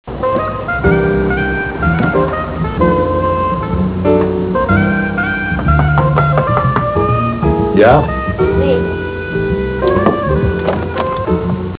Classical/Instrumental
Comment: jazz